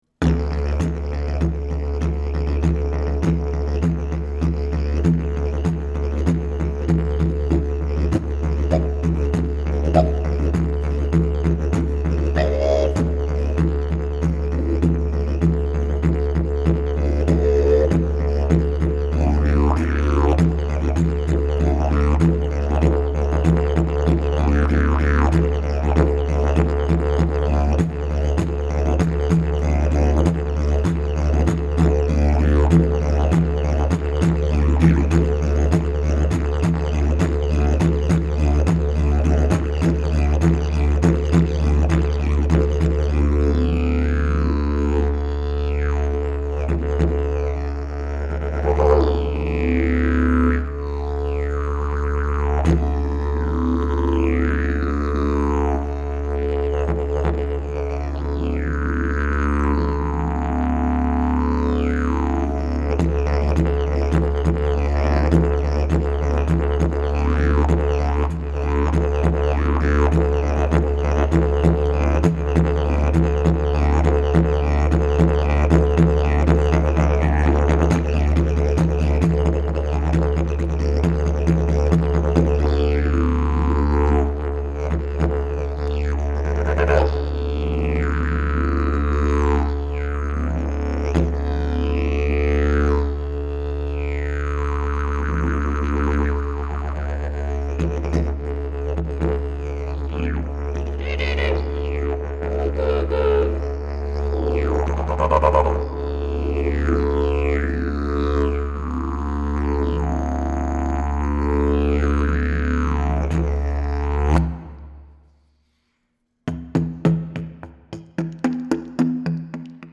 Key: D Length: 69" Bell: 7" Mouthpiece: Bloodwood, Red Zebrawood, Sapele Back pressure: Very strong Weight: 4.2 lbs Skill level: Any
Didgeridoo #613 Key: D